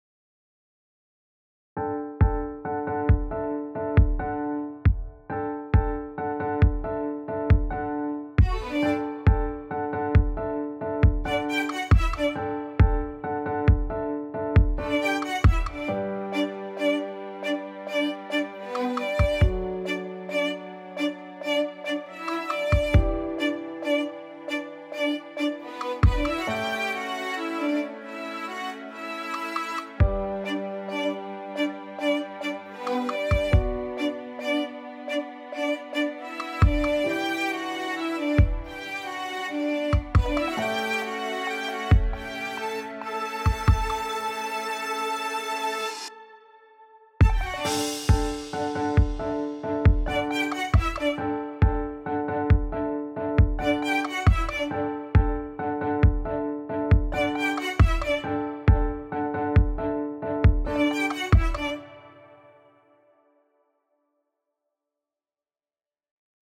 ショートポップ明るい
BGM